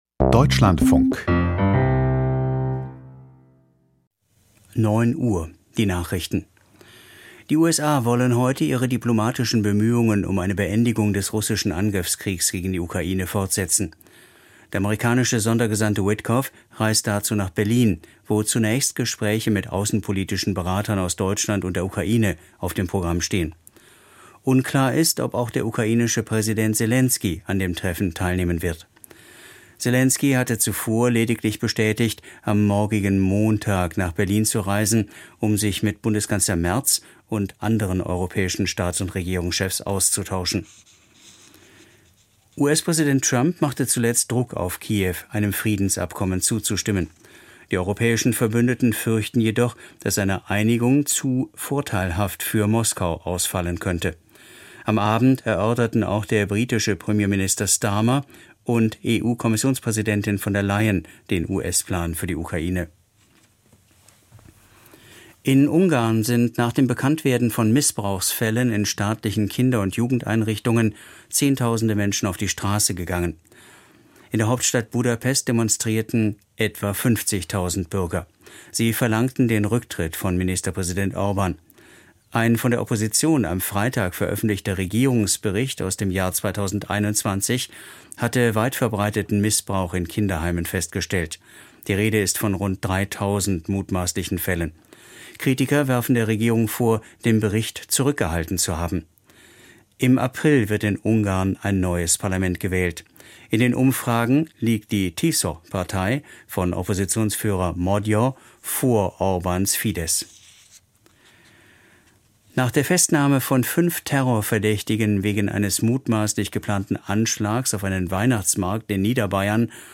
Die Nachrichten vom 14.12.2025, 09:00 Uhr